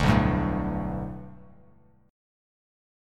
Bm7#5 chord